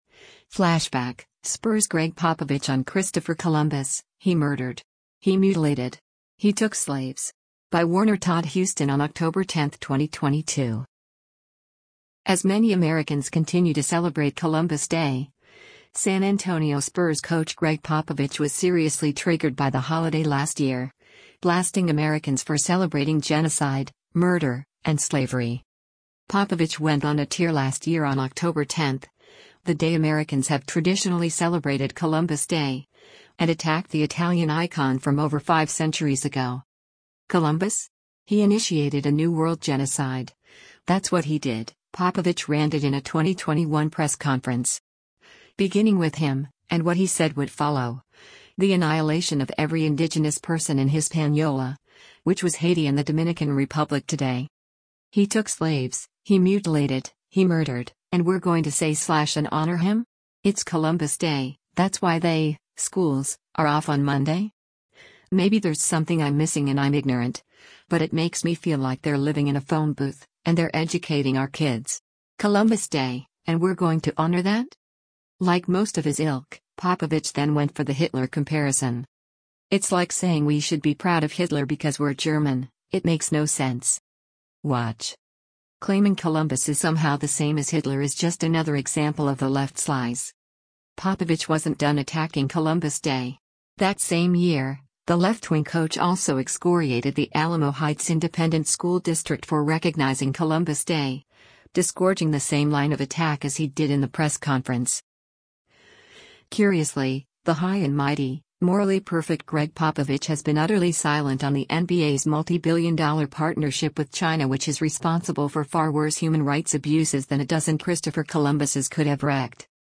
“Columbus? He initiated a new-world genocide, that’s what he did,” Popovich ranted in a 2021 press conference.